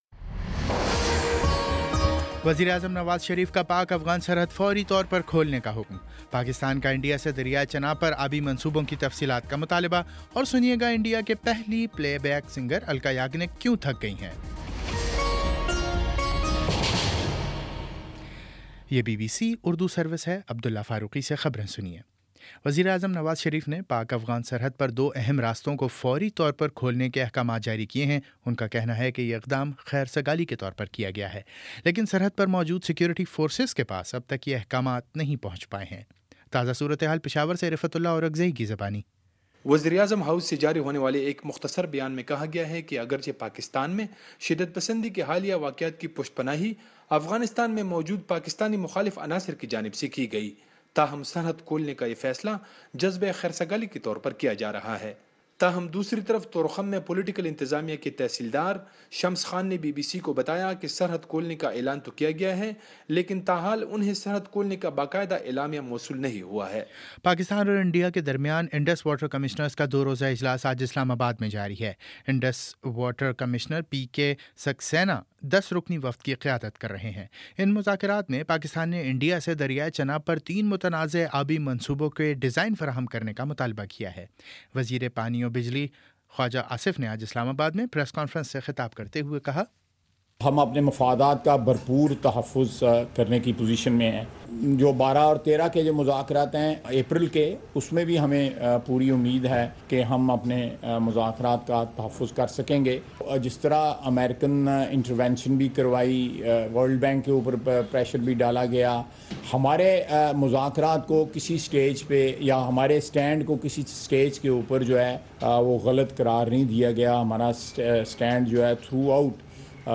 مارچ 20 : شام سات بجے کا نیوز بُلیٹن